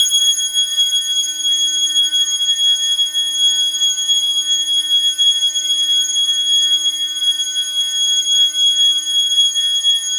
Loop points clicking issue in HISE but not in other samplers